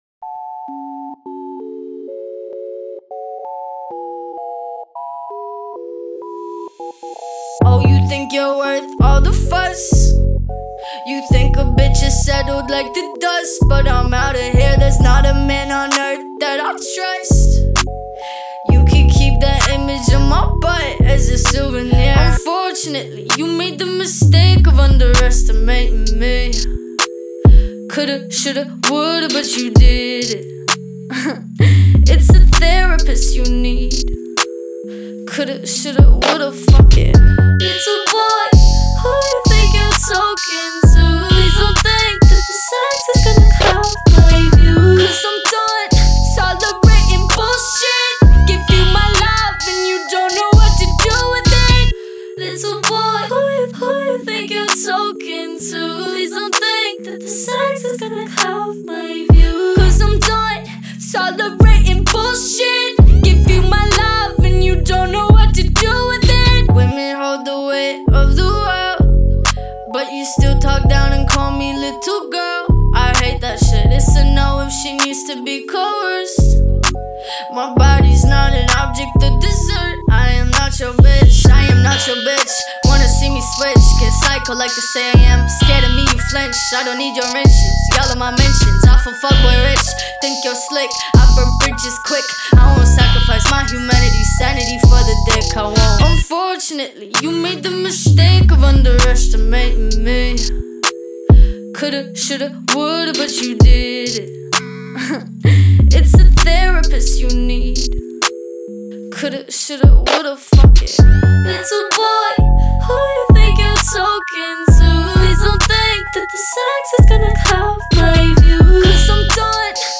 яркая и энергичная песня в жанре поп-рэп